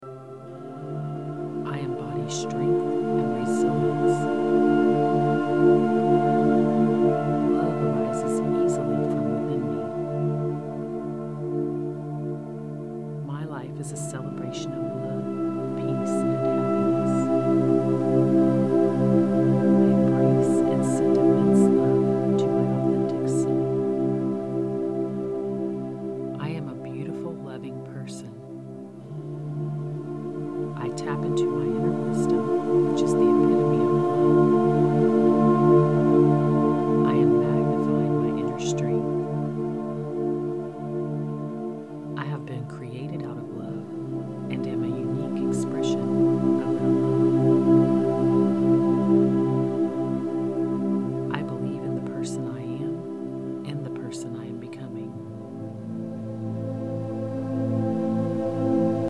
Purification Guided meditation for raising your vibration and clearing negative energy. This guided meditation is intended to raise your overall vibration by clearing away any lower frequency energy you’re carrying.
Enhance_Self_Love_Meditation_Sample.mp3